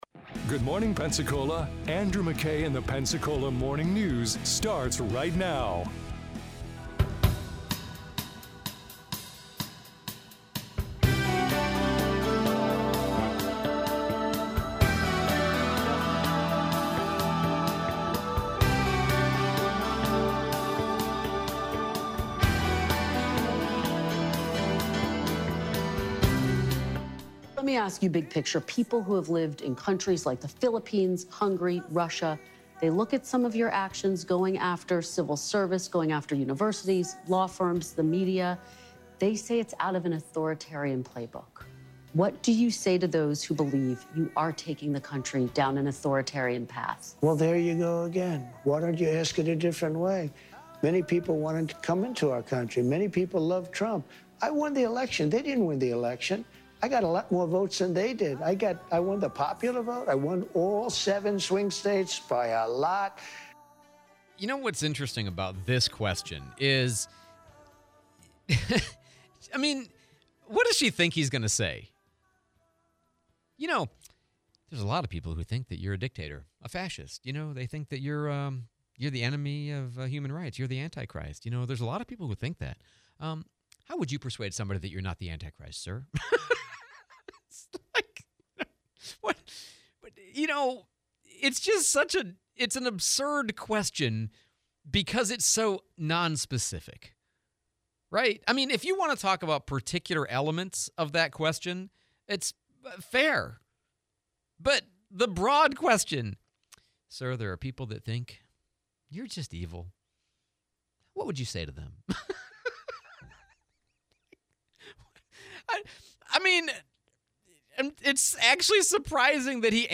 Trump Interview, Fl D-1 Congressman Jimmy Patronis